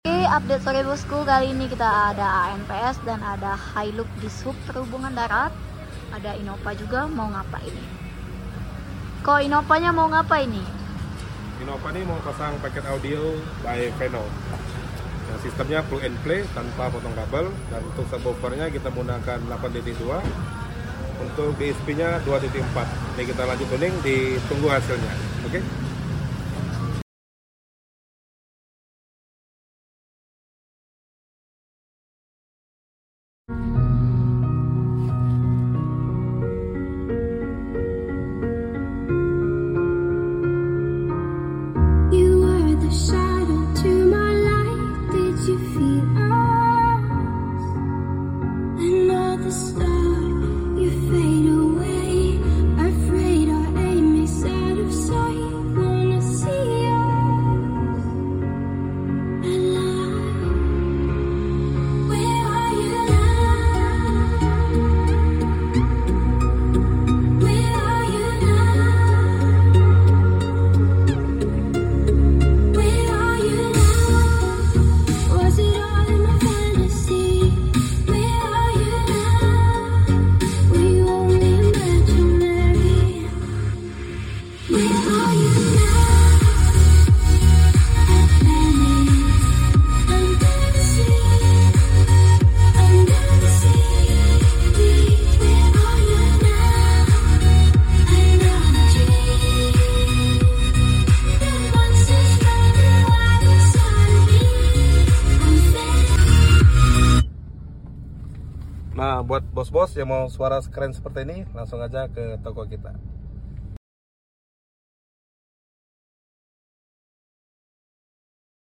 Free tuning, hasil suara jelas dan juga jernih😍